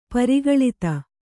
♪ pari gaḷita